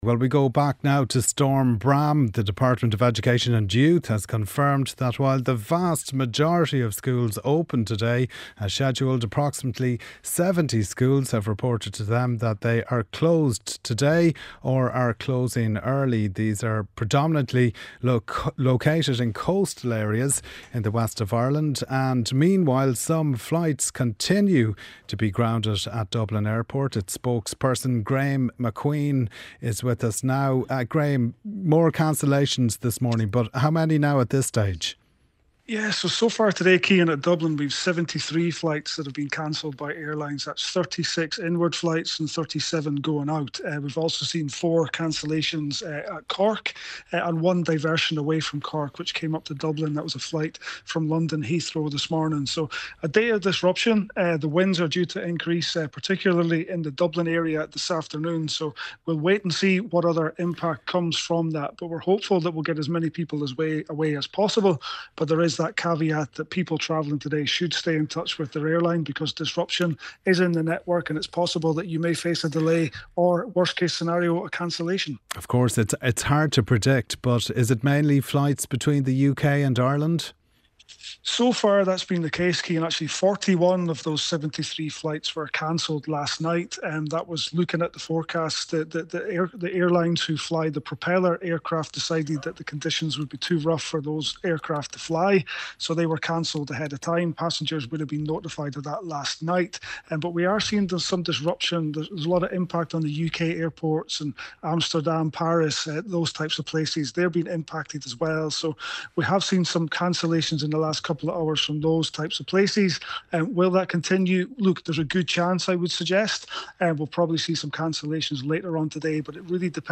News, sport, business and interviews. Presented by Rachael English.